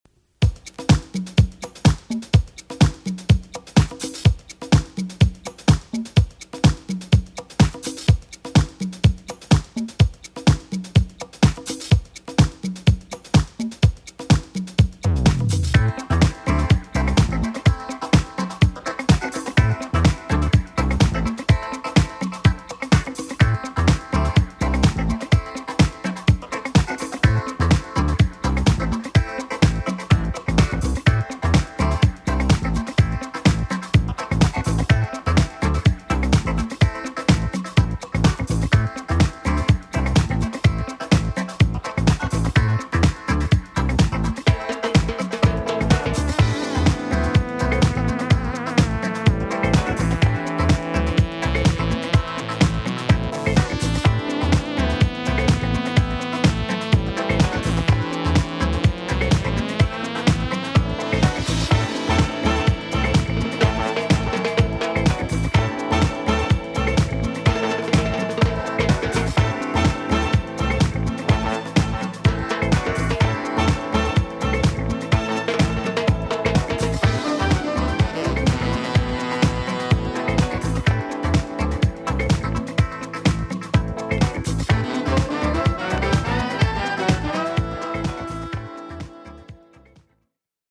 大所帯ディープ・ファンクバンド AFRO FUNK リミックス・シングル 33RPM.